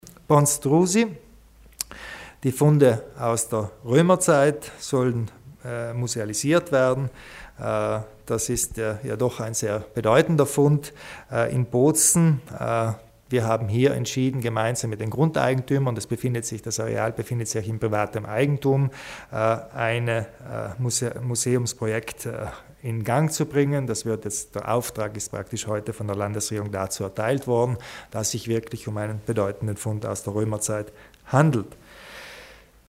Landeshauptmann Kompatscher zur Aufwertung der archäologischen Funde in Gries